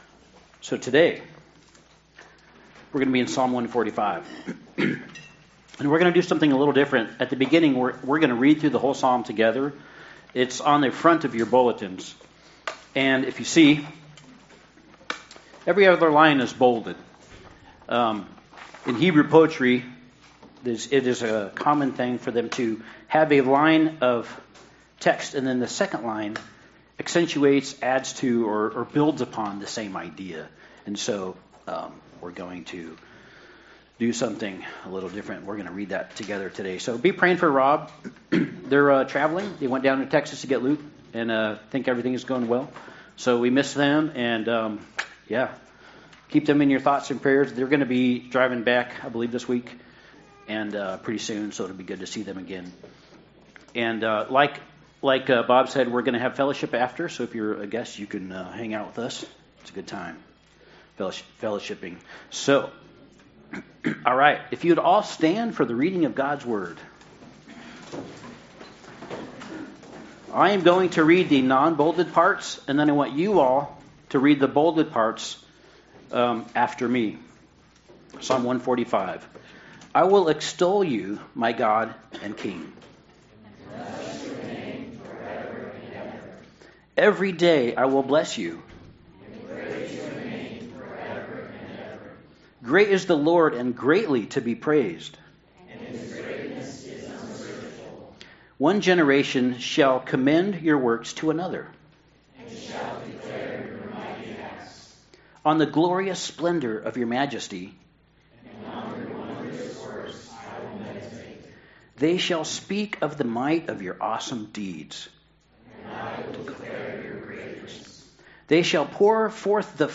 Psalms 145 Service Type: Sunday Service God is the eternal King.